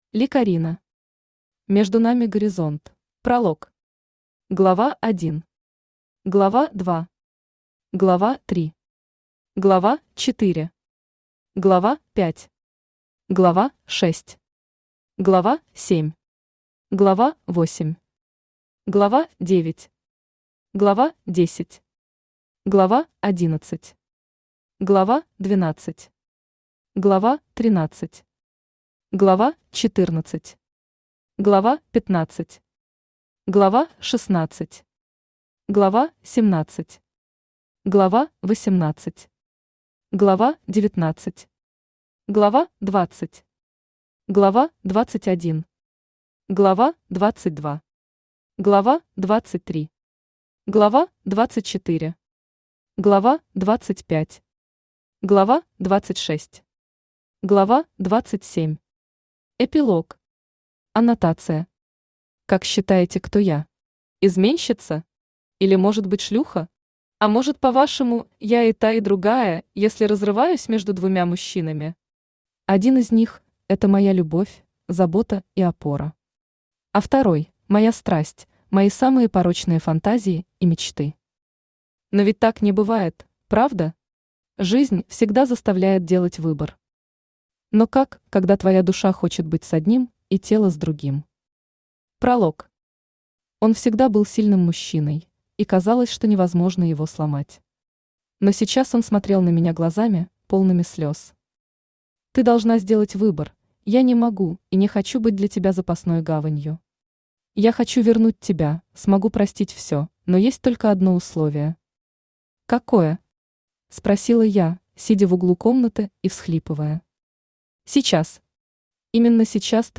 Аудиокнига Между нами горизонт | Библиотека аудиокниг